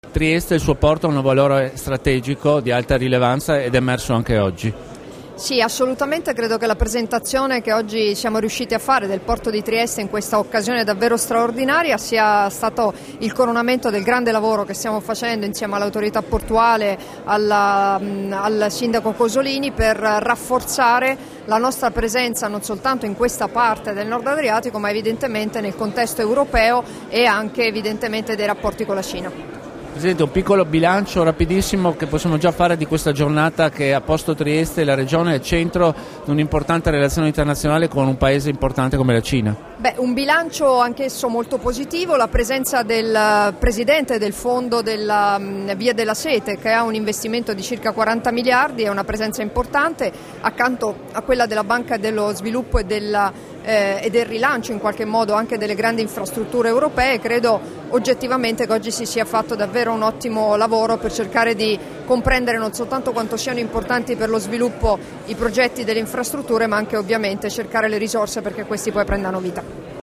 Dichiarazioni di Debora Serracchiani (Formato MP3) [1121KB]
a margine della conferenza internazionale "Supporting local enterprises and SMEs (Small and Medium Enterprises) along China's belt and road initiative in South Eastern Europe", rilasciate a Trieste il 19 maggio 2016